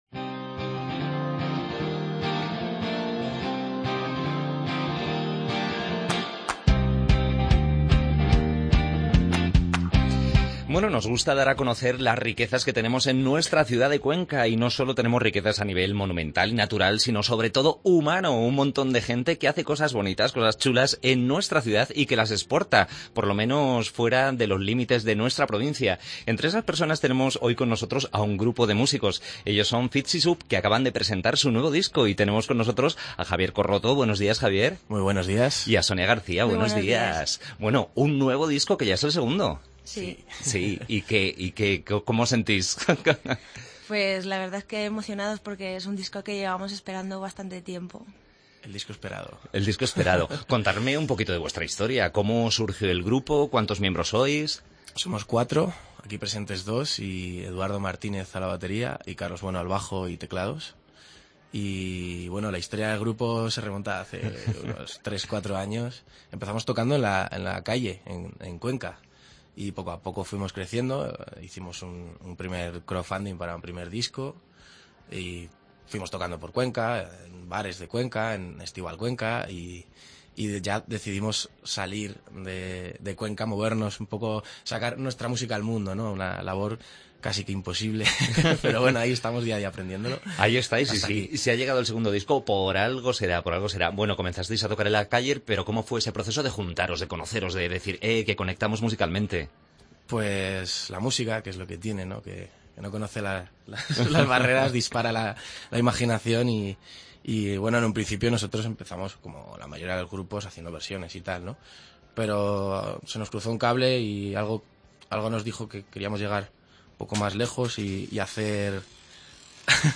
AUDIO: Entrevista al grupo Conquense Fizzi Soup.